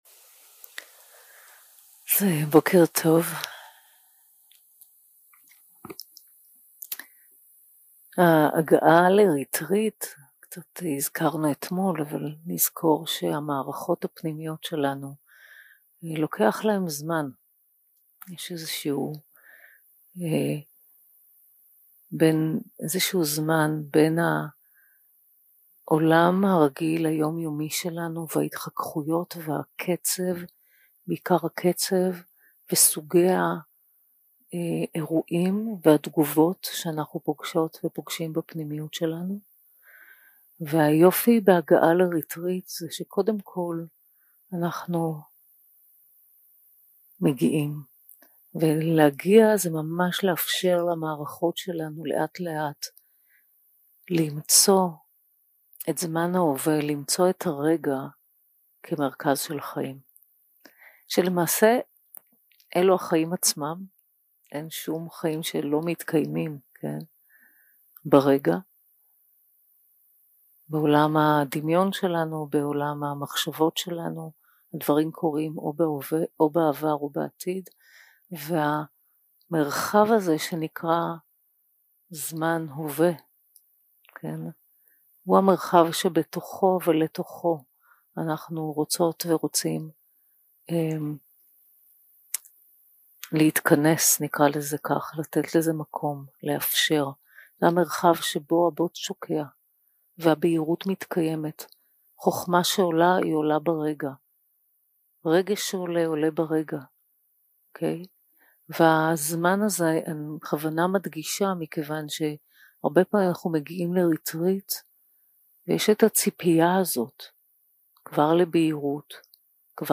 יום 2 – הקלטה 2 – בוקר – מדיטציה מונחית – קבלה בברכה וחזרה לגוף
יום 2 – הקלטה 2 – בוקר – מדיטציה מונחית – קבלה בברכה וחזרה לגוף 0:00 0:00 סוג ההקלטה: Dharma type: Guided meditation שפת ההקלטה: Dharma talk language: Hebrew